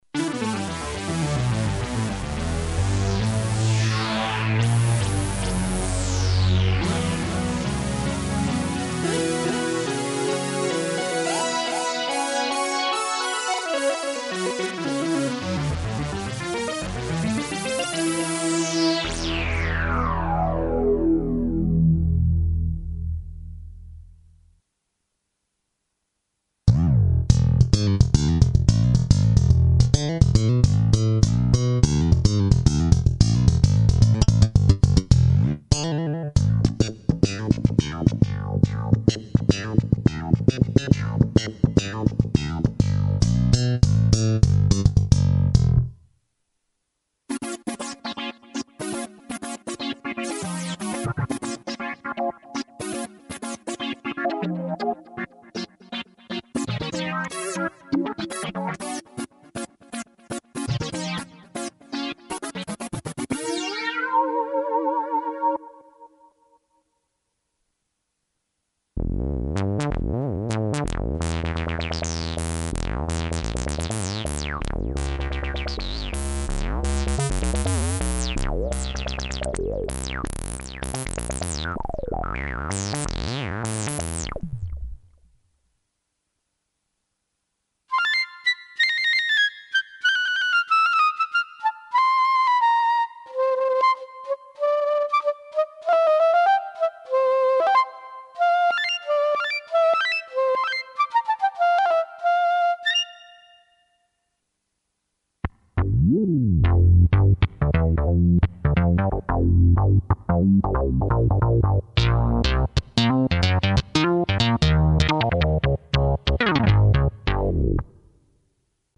factory song with flute, moog